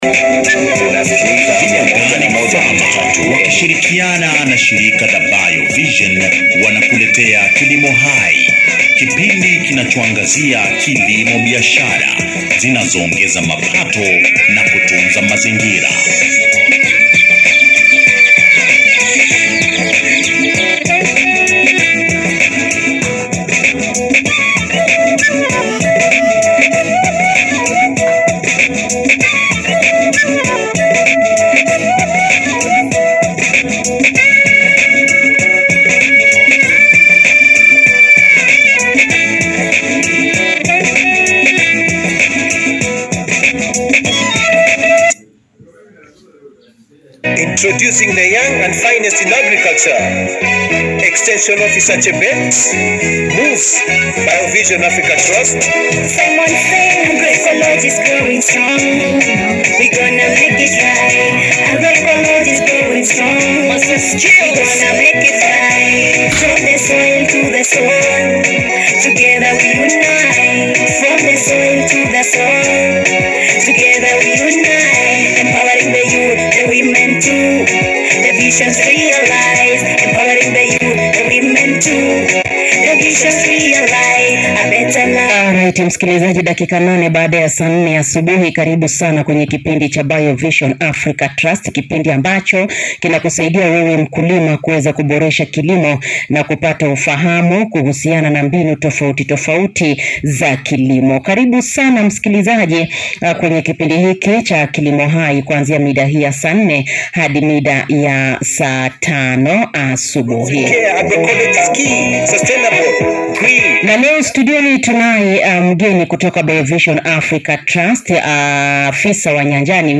If you missed our live session on Integrated Pest Management (IPM) here’s your chance to catch up! 🌿Learn how farmers are using eco-friendly methods to control pests while protecting soil health, biodiversity, and the environment.